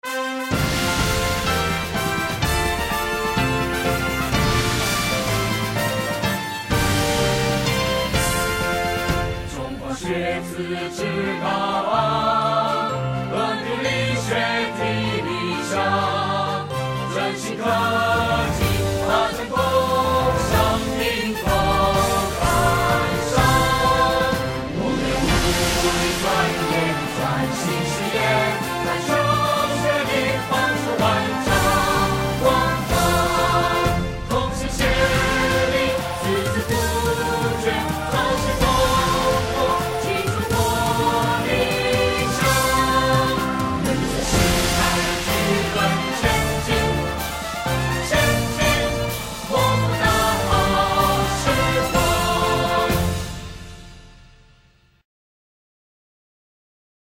校歌教唱